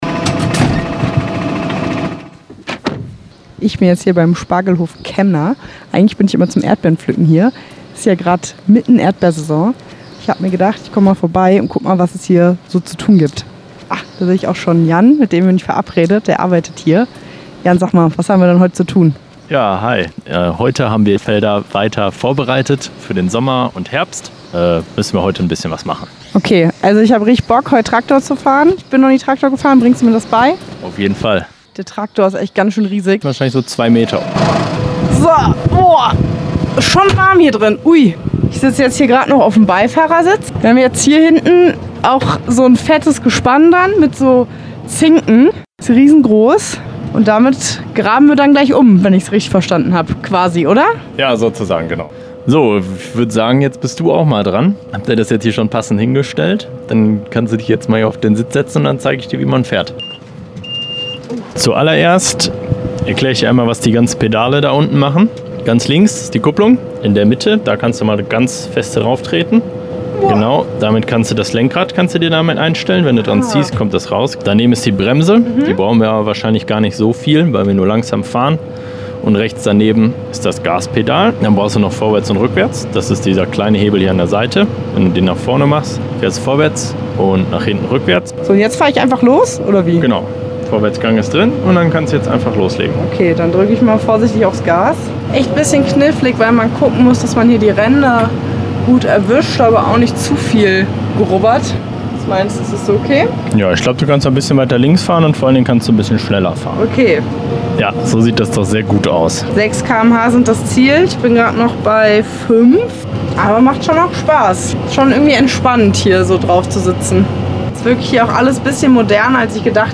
Reportage  Ressort